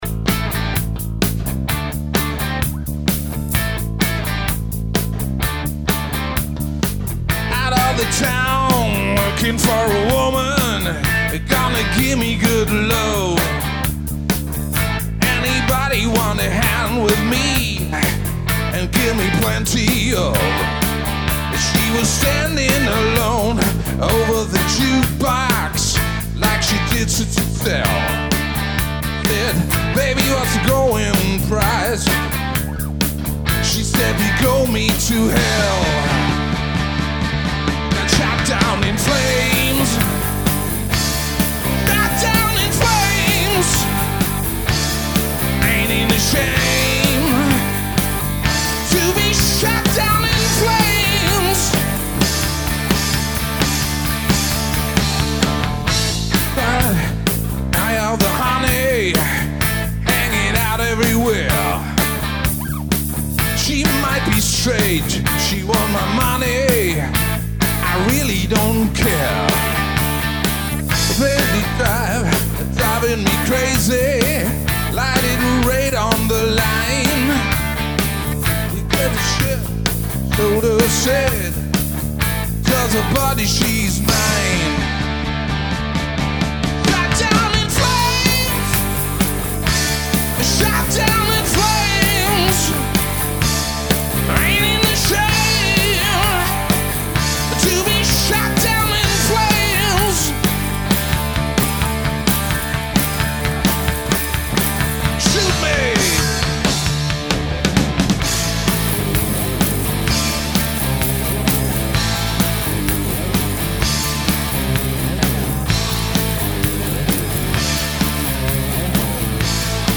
Hier mal ein kurzer Üraum-Mitschnitt mit dem Euro 5 TWX. Line ins Pult, Cubase 8.5 mit Slate VMX Mix, quick and dirty. Die HiHat klingt so scharf, weil das eine gelochte Übungshihat ist, die ziemlich scheusslich klingt, aber deutlich leiser ist als die richtigen Becken. your_browser_is_not_able_to_play_this_audio